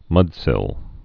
(mŭdsĭl)